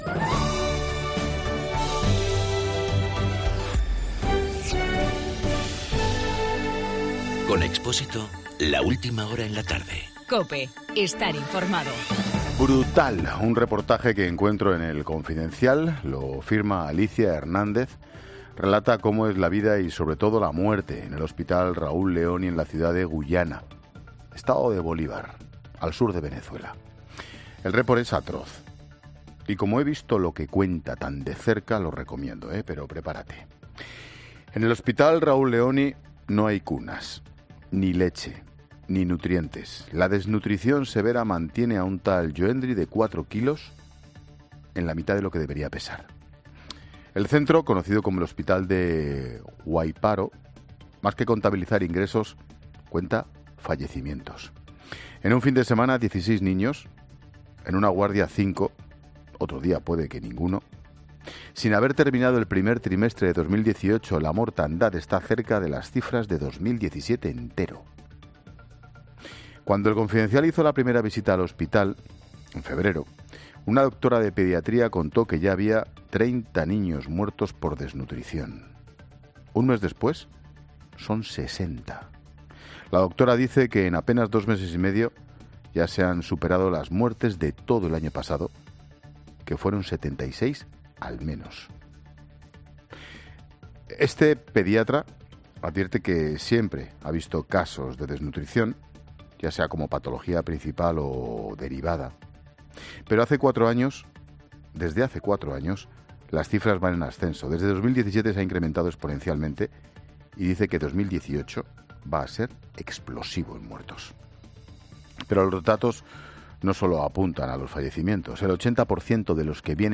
Monólogo de Expósito
El comentario de Ángel Expósito según un reportaje leído en el Confidencial.